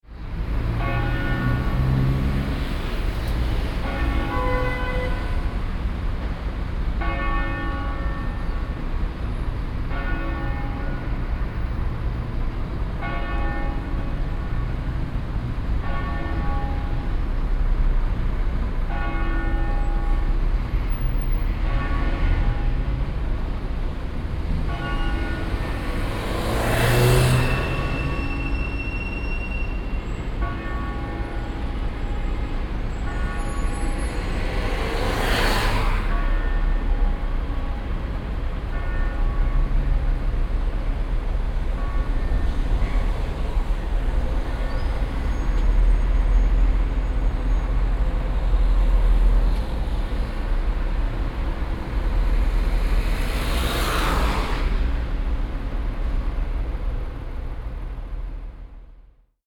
Campane e traffico - Città Metropolitana di Torino...
Rumore
Torino, presso il Rondò della Forca
Microfoni binaurali stereo SOUNDMAN OKM II-K / Registratore ZOOM H4n
Campane-e-traffico.mp3